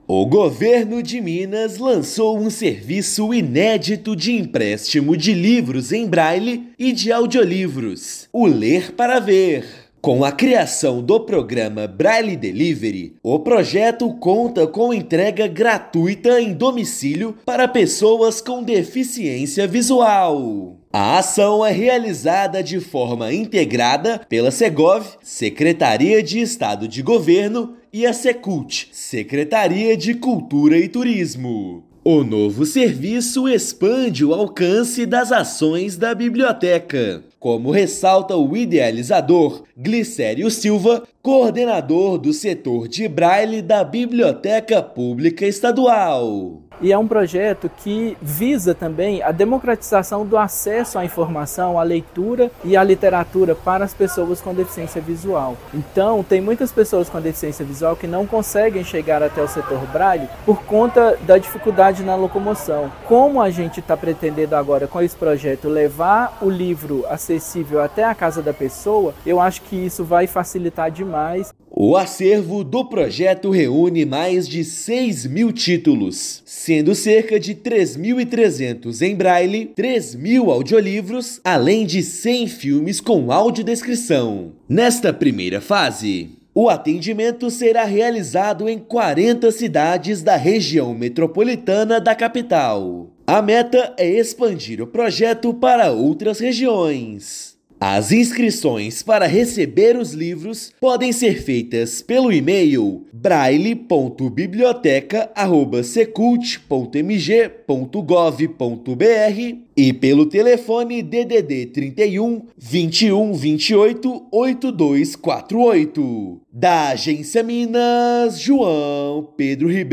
[RÁDIO] Governo de Minas amplia democratização e acesso aos livros com o Projeto Ler Para Ver (Braille Delivery)
Iniciativa inédita de entrega domiciliar fica disponível mediante cadastro na Biblioteca Pública Estadual de Minas Gerais. Ouça matéria de rádio.